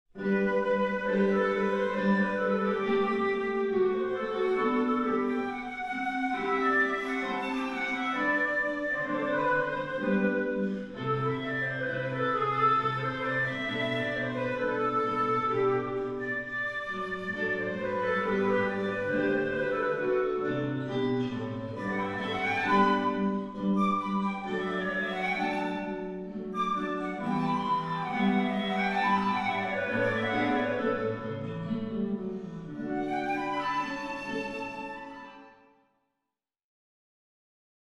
flute/guitar
reverb sound, as heard over the rear speakers in the listening
I imagine sitting in my 5-ch listening room listening to only the two surround speakers of a 5-ch recording of the duo playing in a concert hall.
I notice that the surrounds have an obvious "room sound" as created by the rear-facing virtual mics used in PureVerb™.